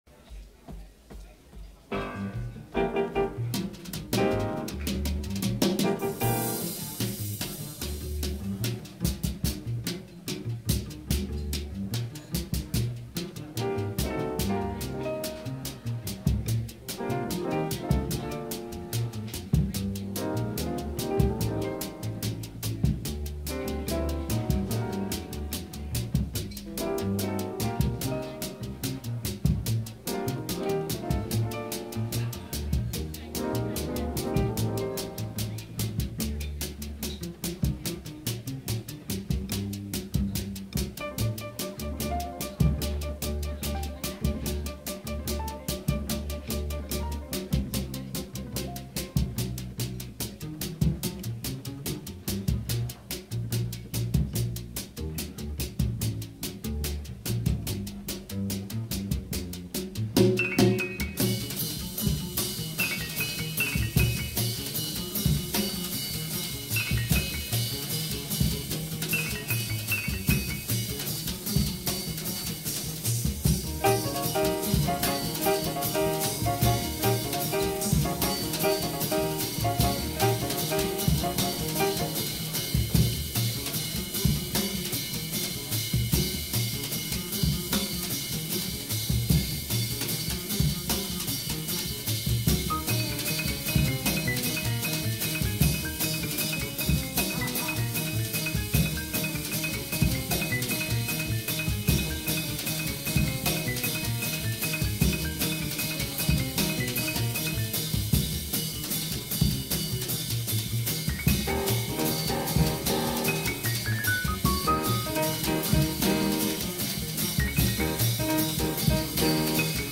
jazz standard